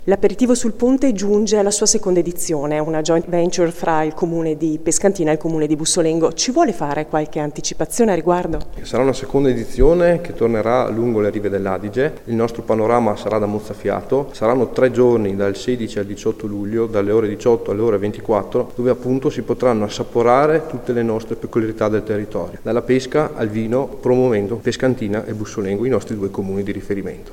L’assessore alle Manifestazioni di Pescantina, Nicolò Rebonato:
Foto e interviste